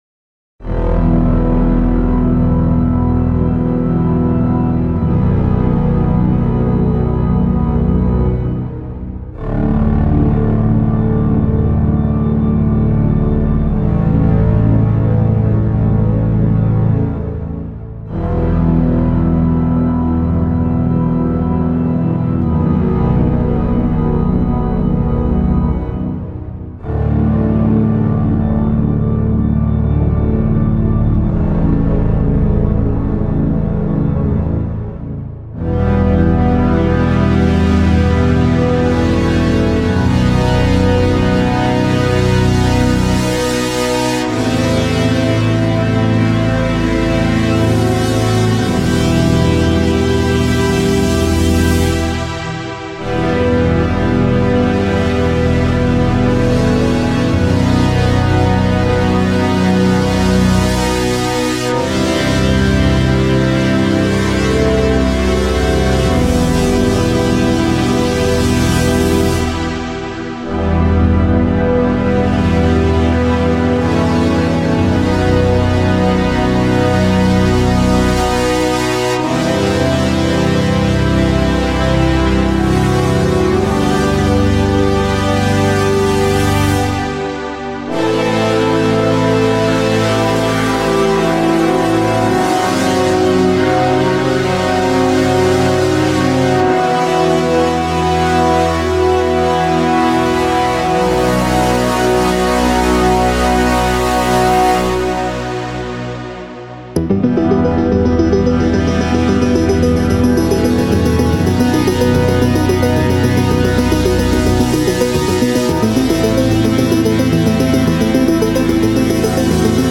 Synth Cover